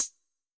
UHH_ElectroHatC_Hit-30.wav